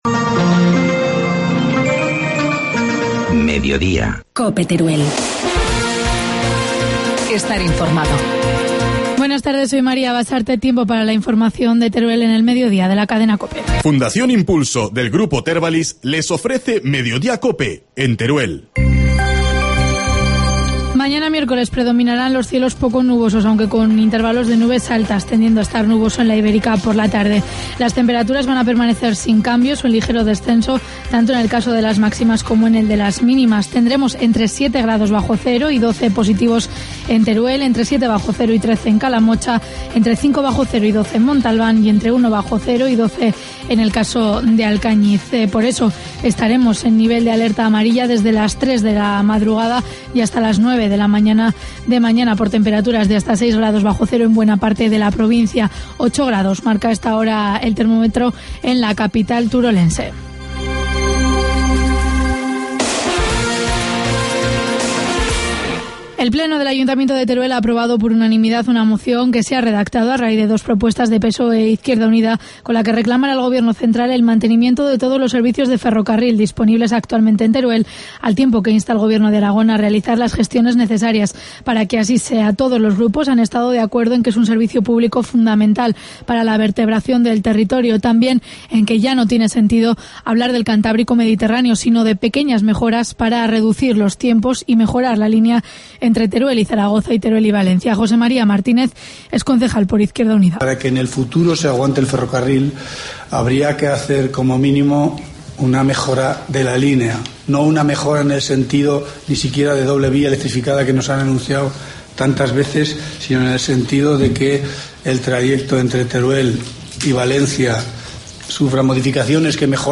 Informativo mediodía, martes 8 de enero